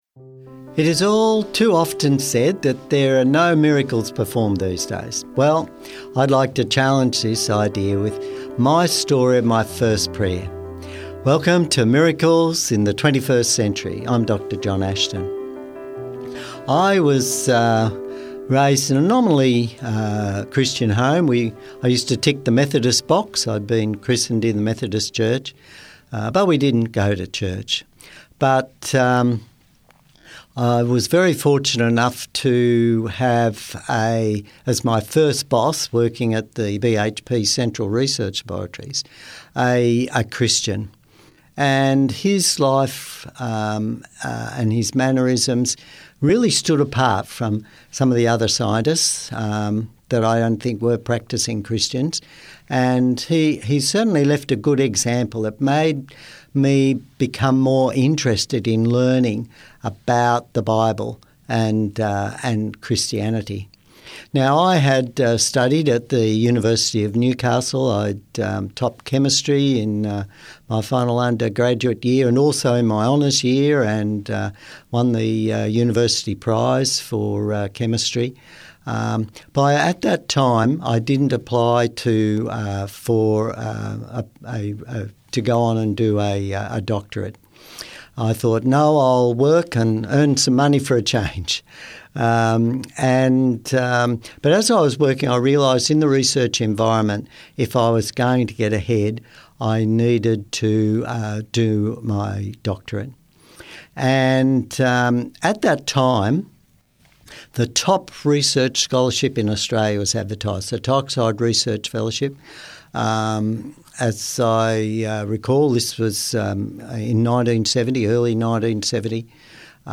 Miracles? In the 21st Century? Yes, listen to people share real life miracle stories, not only from recent times but also amazing stories from their past.